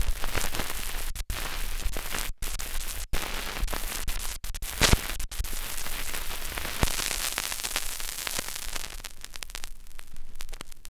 DESTROY   -L.wav